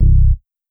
Bass (RUSTY).wav